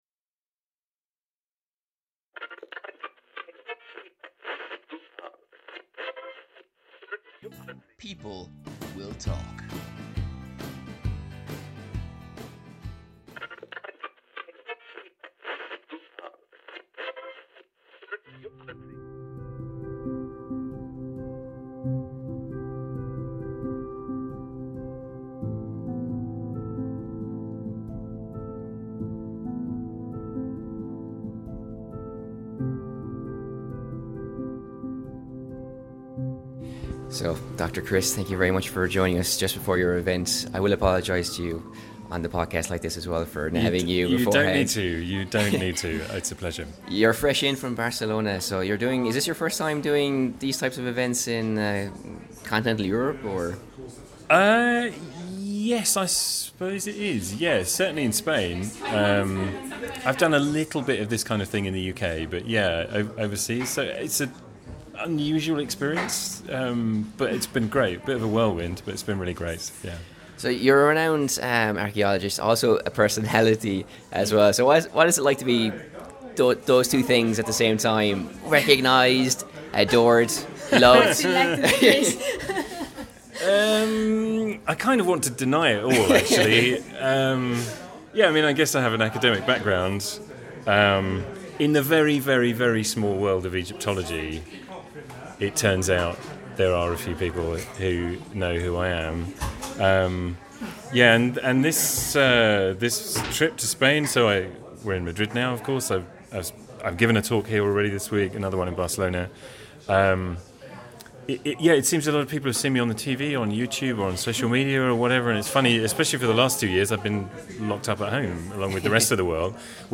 We got our chat on with a BBC historian.
Madrid's wonderful Secret Kingdoms bookshop hosted our conversation with Dr Chris Naunton , who is an author, TV personality and Egyptologist extraordinaire.